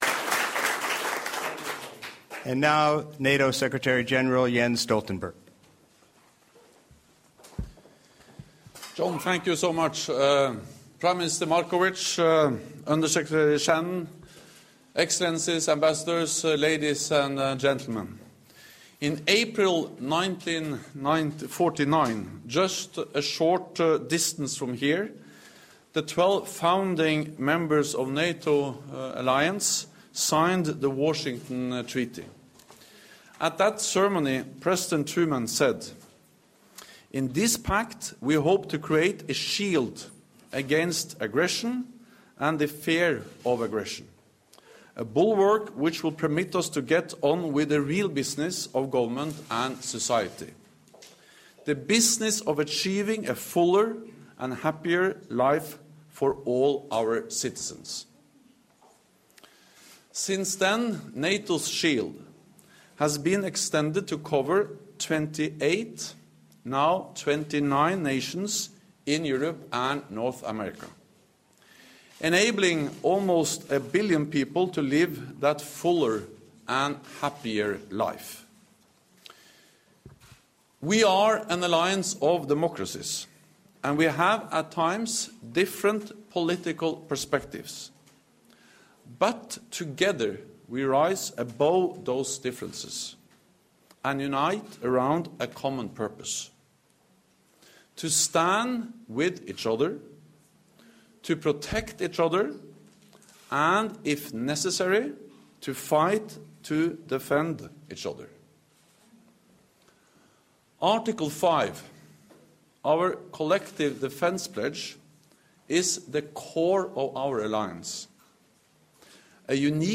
Montenegro became NATO’s newest member on Monday (5 June 2017), upon depositing its instrument of accession to the North Atlantic Treaty with the US State Department in Washington DC. At a ceremony marking the occasion, NATO Secretary General Jens Stoltenberg underlined that Montenegro’s accession to the Alliance contributes to international peace and security, and sends a strong signal that NATO’s door remains open. The ceremony was attended by Prime Minister Duško Marković and by the Minister of Foreign Affairs of Montenegro Srdjan Darmanović and was hosted by the United States Undersecretary for Political Affairs Thomas A. Shannon.